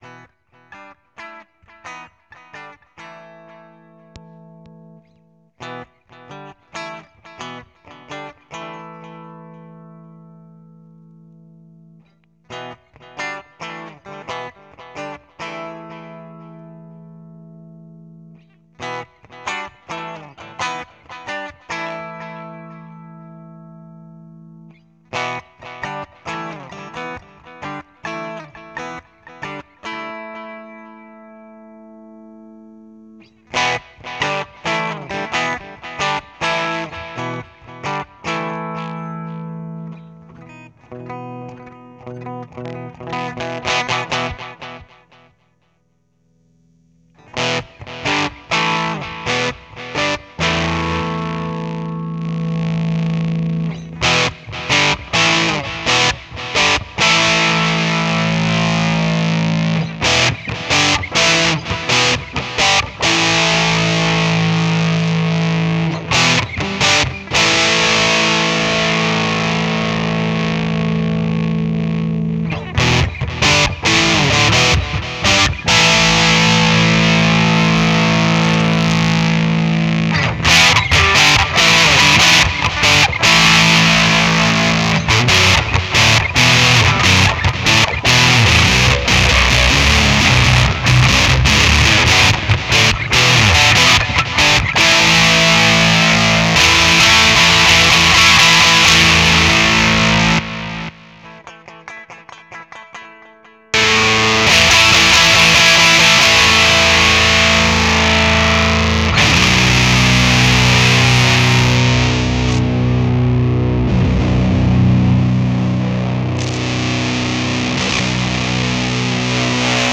Colorsound Overdriver sound clips: Insane boost and fuzz
The Colorsound Overdriver produces the most insane amounts of boost and fuzz of any 9 V-powered stompbox.
Audio clips Colorsound Overdriver ( MP3 , 5.5 MB ) Colorsound Overdriver (Ogg Vorbis, 4 MB ) In this sound clip, I’m playing my Ibanez 2027XVV into the Overdriver and then into my Boss VF-1 which adds amp simulation and a bit of reverb and delay. I gradually increase the volume and then tweak the bass and treble knobs. Warning : The clip starts at a very low volume.
colorsound-overdriver.ogg